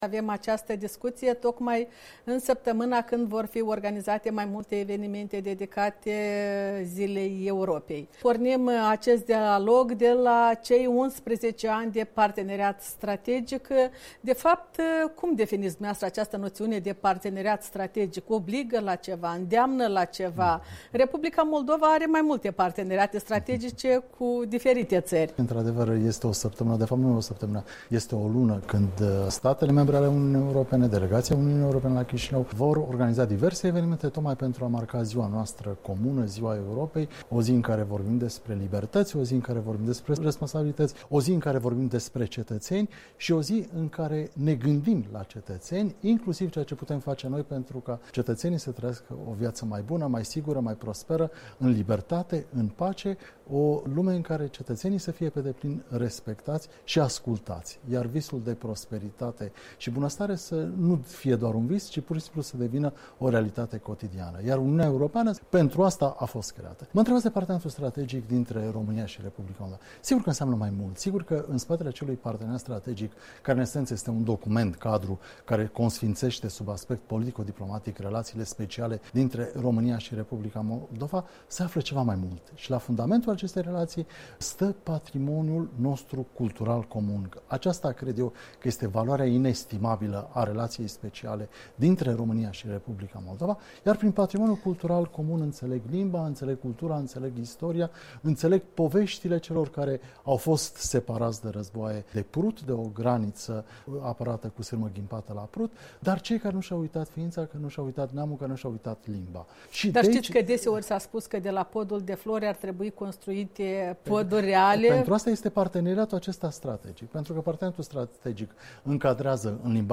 Interviu cu ambasadorul Daniel Ioniță